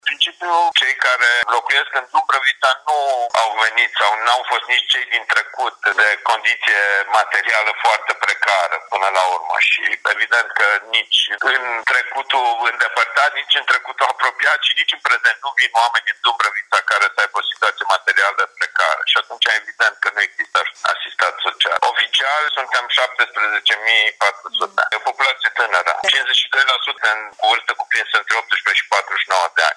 La Dumbrăvița, locuitorii nu au o condiție materială precară, în plus, mai bine de jumătate din populație are între 18 și 49 de ani, spune primarul Horia Bugărin.